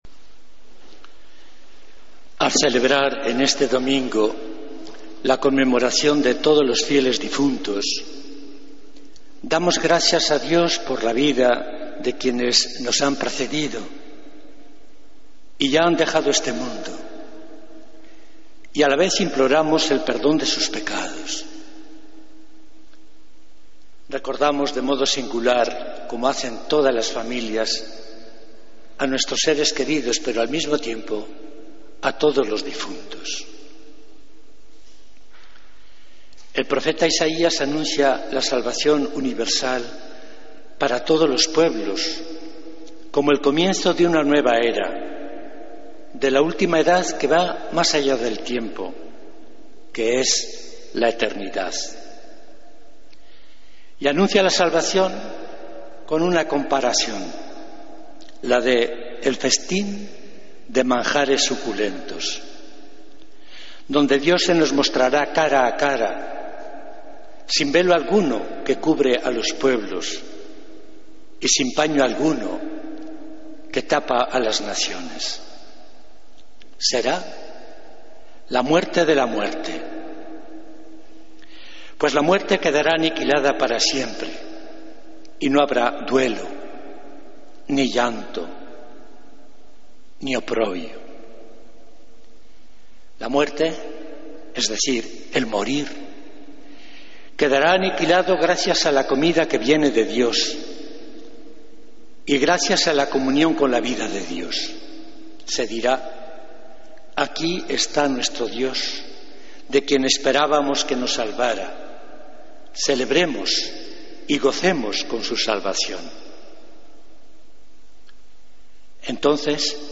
Homilía del Domingo 2 de Noviembre de 2014